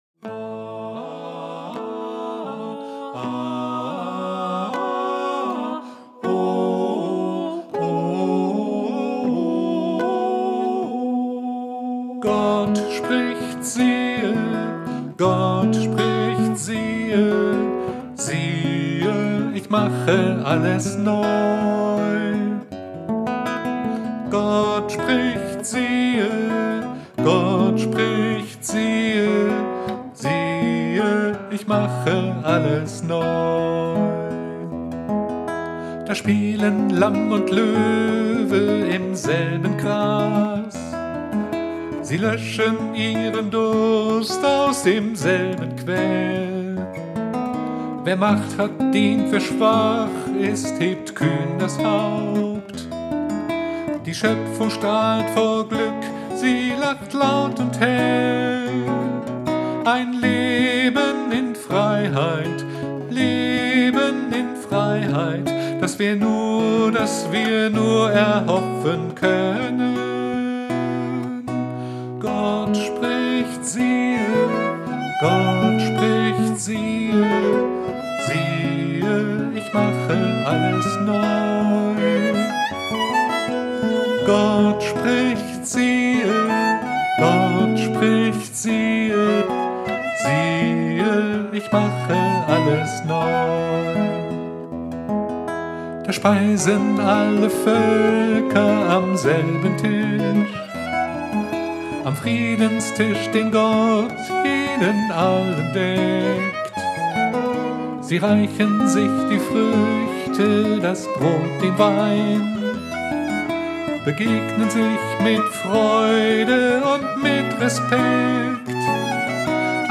Ein Tipp: das Lied am besten mit Kopfhörern hören !!
Gitarre und Gesang
Geige
backing vocals